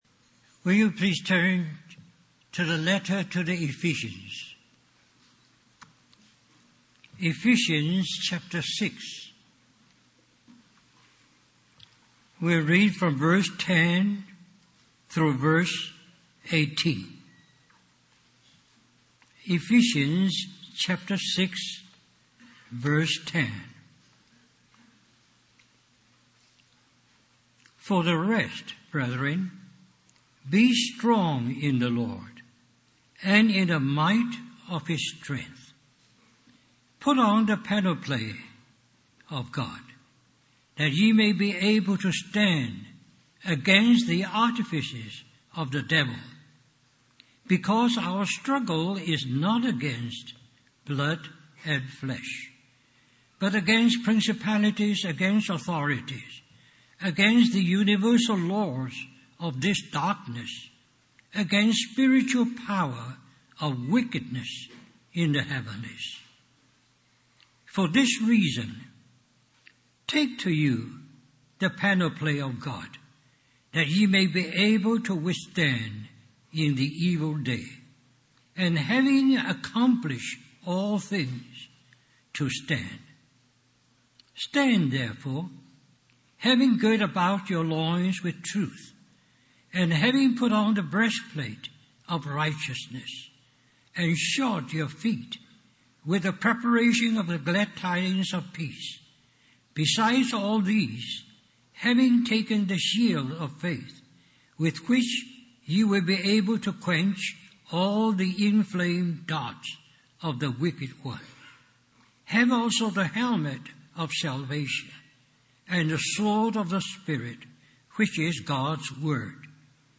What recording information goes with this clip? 2011 Christian Family Conference